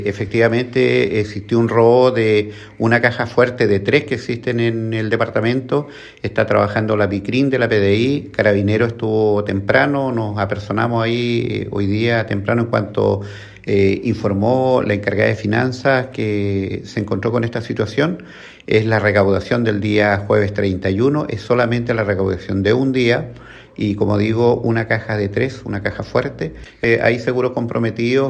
La información fue confirmada por el alcalde Andrés Reinoso, quien detalló que se trató del robo de una de las tres cajas fuertes del departamento municipal.
alcalde-la-union.mp3